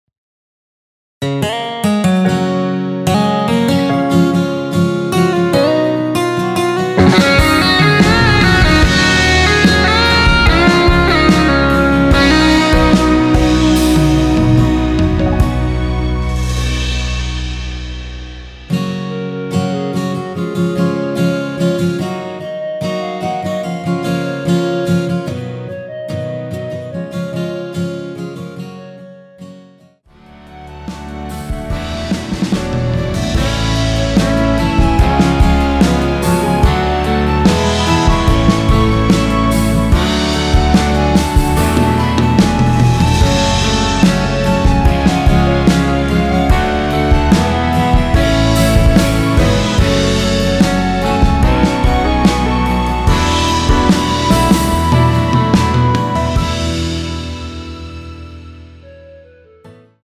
원키에서(-1)내린 (2절 삭제)멜로디 포함된 MR입니다.
Gb
앞부분30초, 뒷부분30초씩 편집해서 올려 드리고 있습니다.